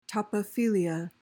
PRONUNCIATION:
(top-uh-FIL-ee-uh)